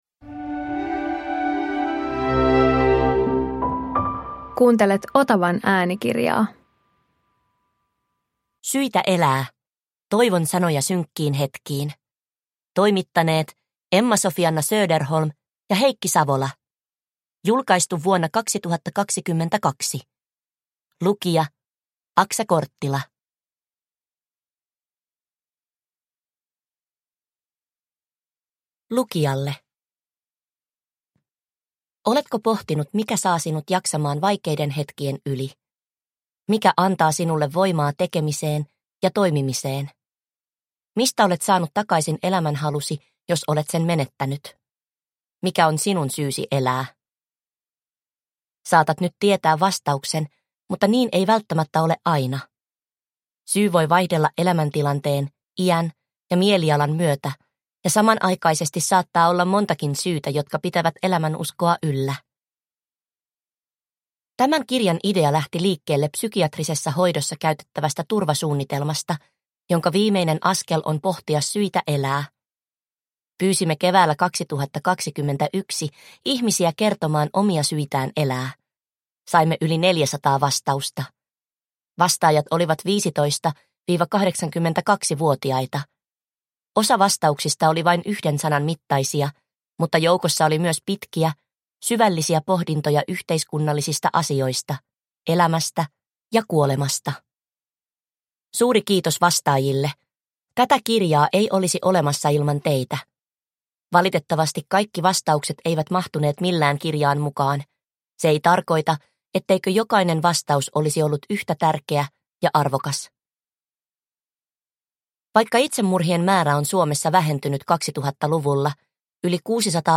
Syitä elää – Ljudbok